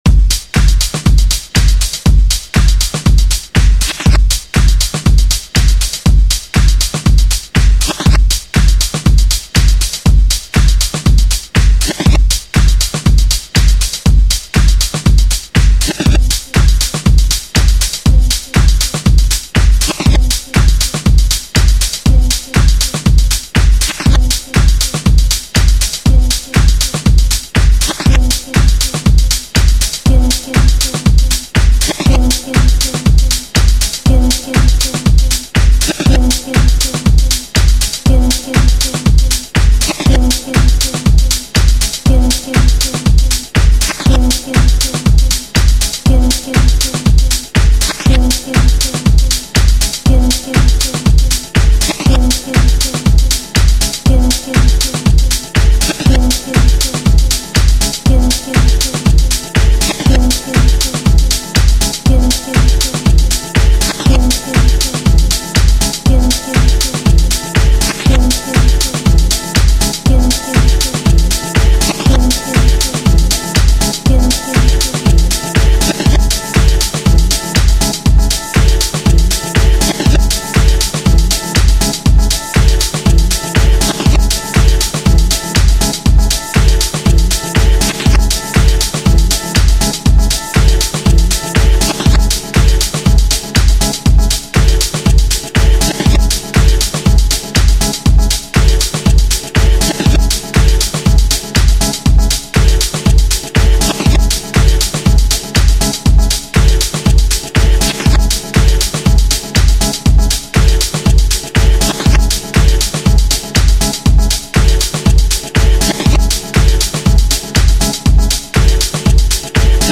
dance/electronic
House
Electro